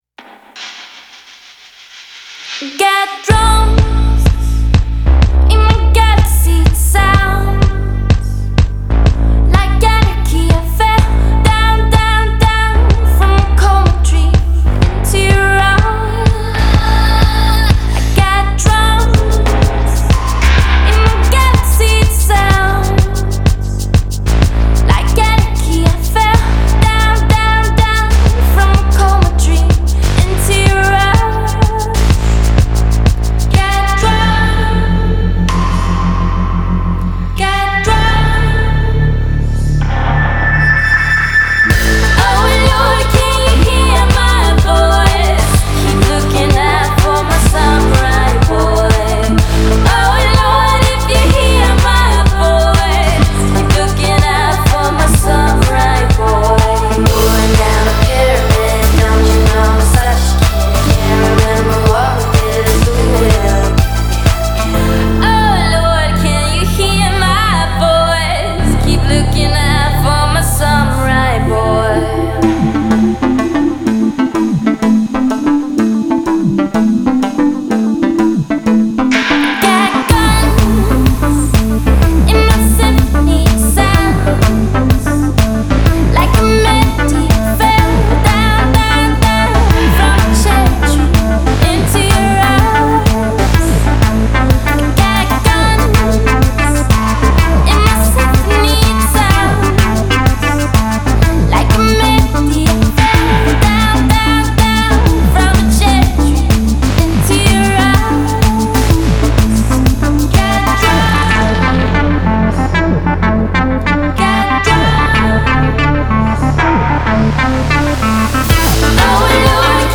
Genre: Pop, Electro-Pop